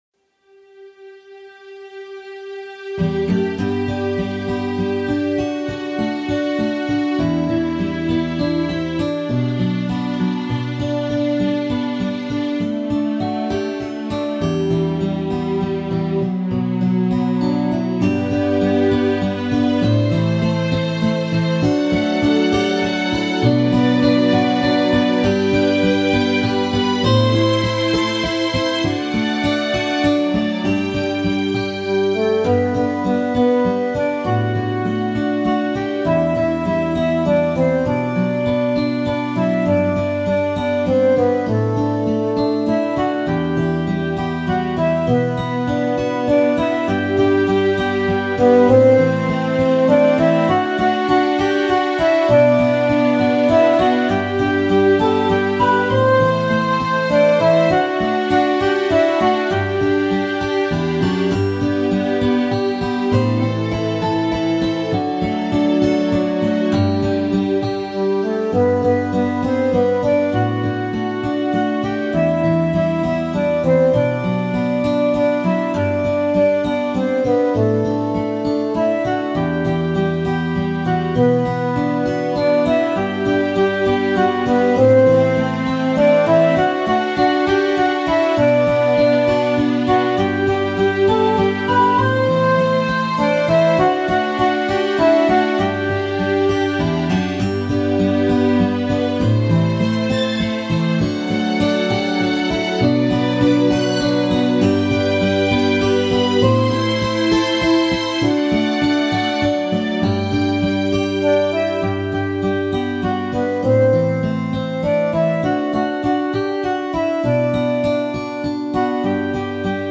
Главная » Музыка » Минуса » 2010